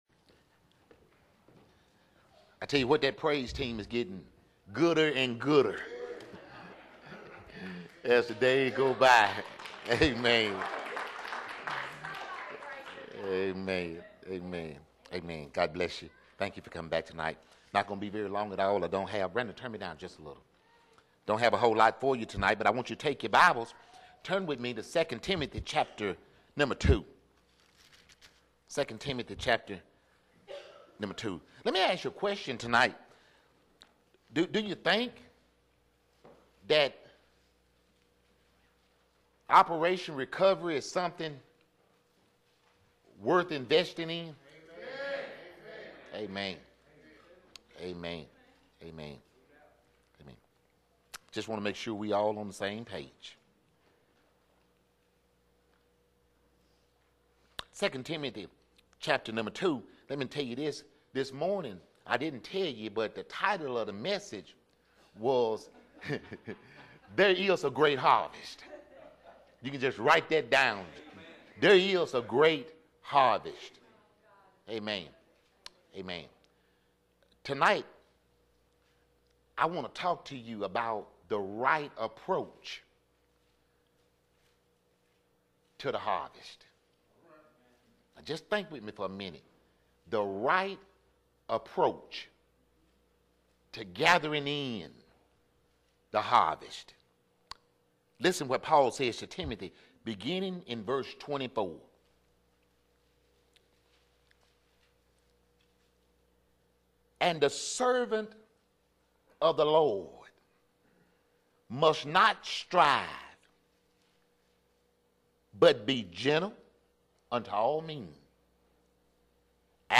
Solid Rock Baptist Church Sermons